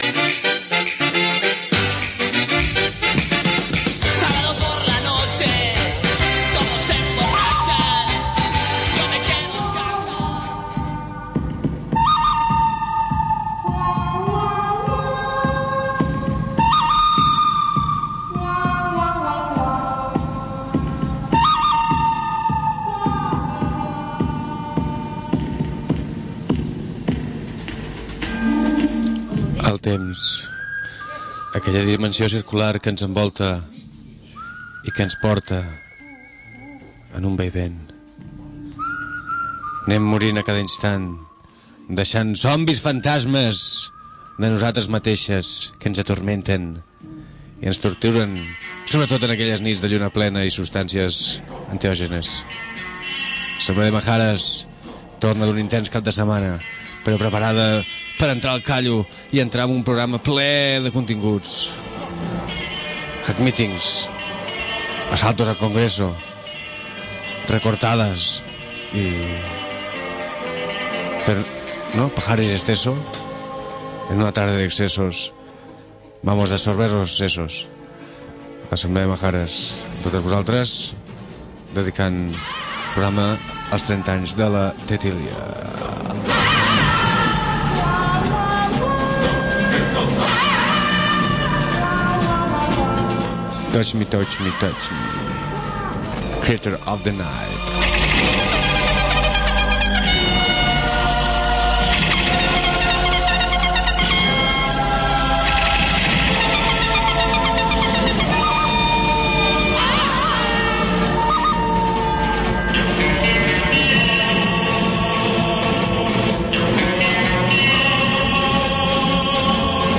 Programa de connexions telefòniques per portar-nos lluites internacionals que també ens toquen ben d’aprop.